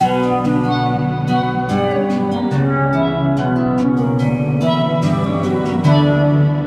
Tag: 144 bpm Trap Loops Synth Loops 4.49 MB wav Key : F